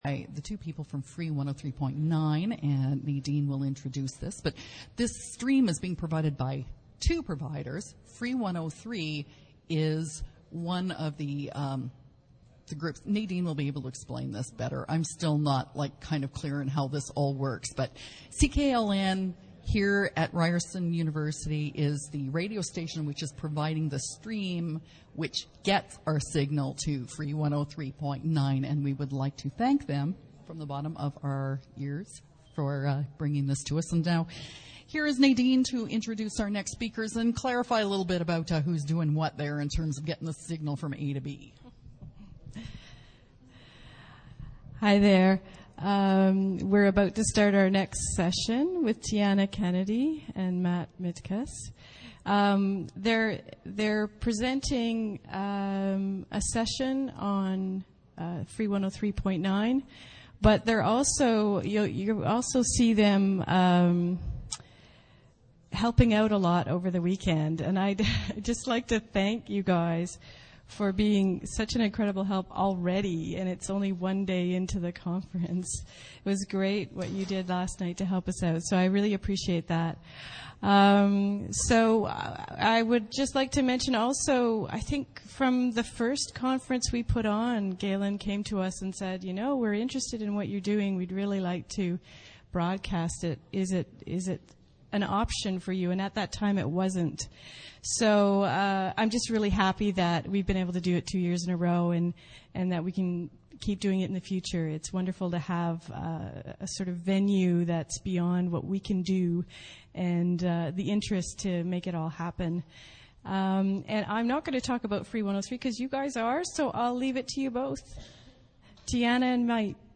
This panel, streamed live on free103point9 Online Radio from Toronto, Canada, introduces audiences to free103point9 and Transmission Arts defined as experimental practices in radio, video, installation, and performance utilizing the wireless spectrum. The discussion will include: the history of free103point9; touring transmission projects such as Tune(In))) , Radio 4x4 , and Microradio Sound Walk ; as well as free103point9 exhibition, performance, online radio, education, and residency programs.